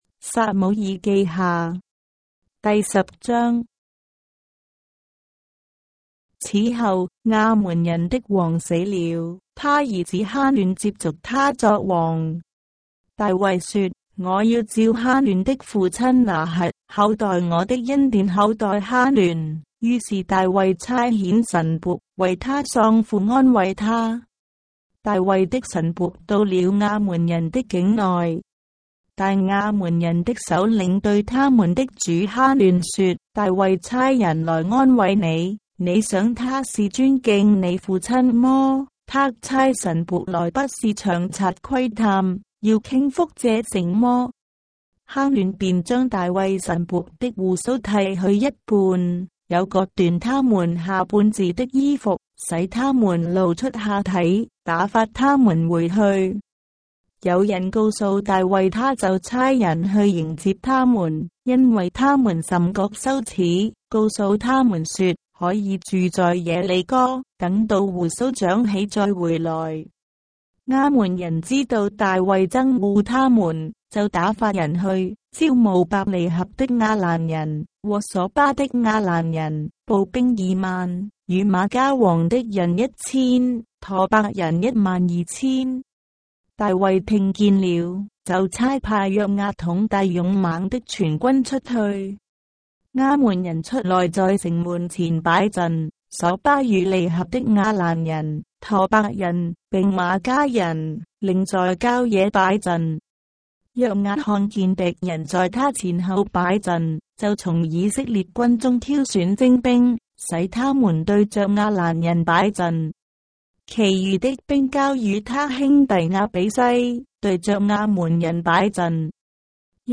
章的聖經在中國的語言，音頻旁白- 2 Samuel, chapter 10 of the Holy Bible in Traditional Chinese